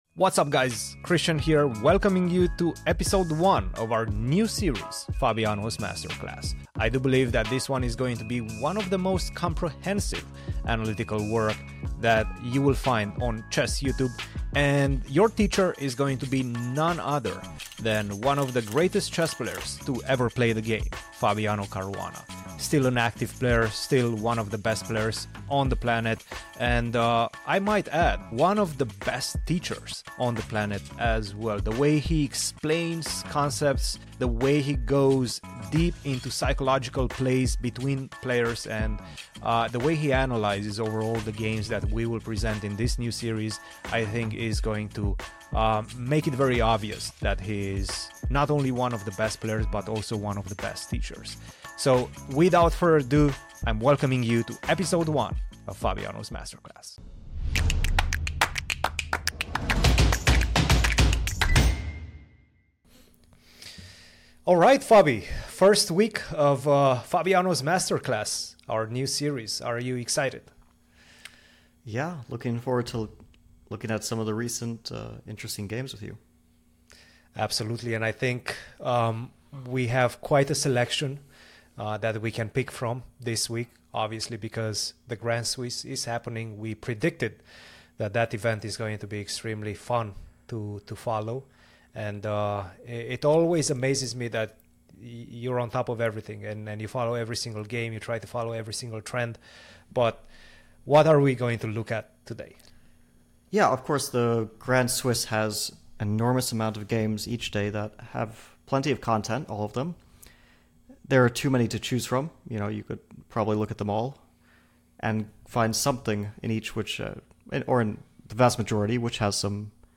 This weekly new series aims to provide the highest level of chess analysis, presented by none other than one of the greatest chess players to ever play the game, Fabiano Caruana. Episode 1 focuses on the battle between 16 yo Abhimanyu Mishra, and WC Gukesh.